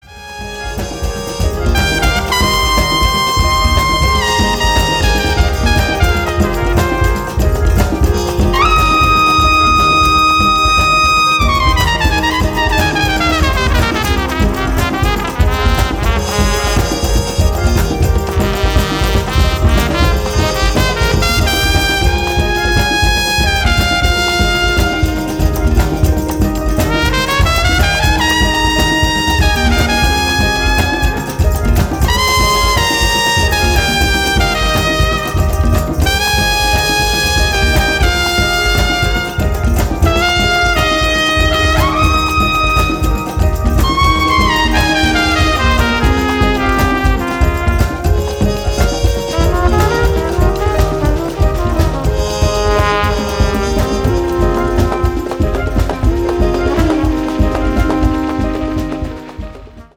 Trumpeter